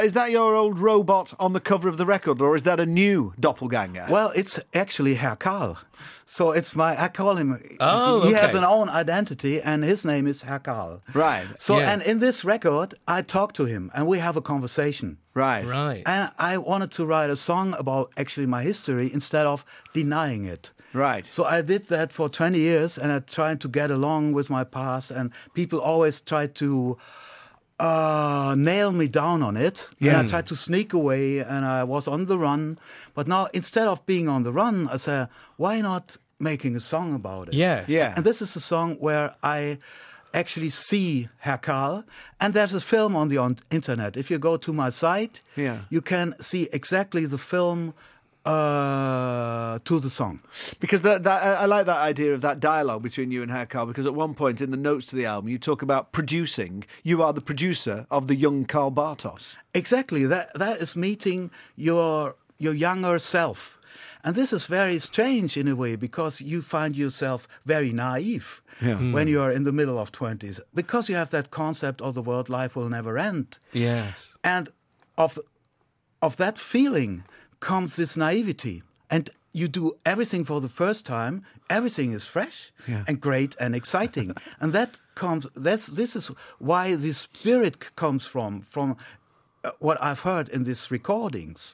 Karl Bartos formerly of Kraftwerk talks to Mark Radcliffe and Stuart Maconie about how he used to hide his from his past, and how he has now embraced it on his song Without A Trace Of Emotion.